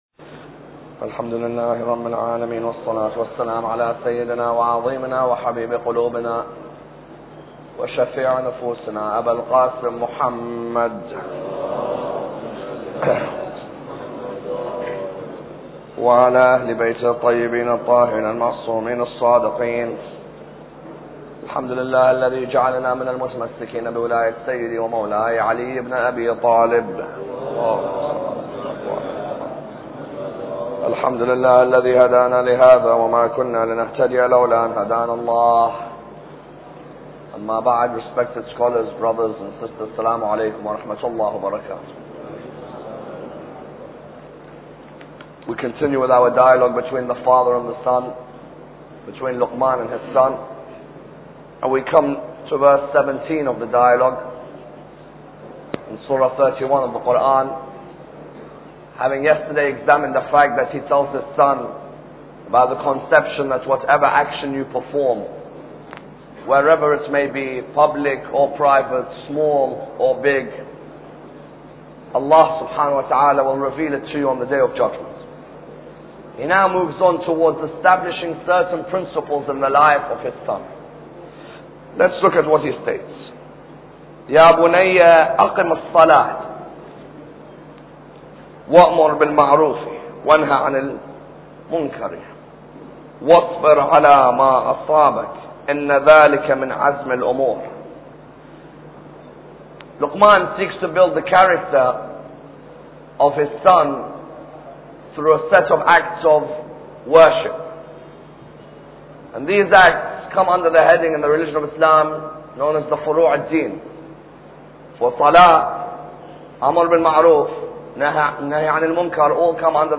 Lecture 11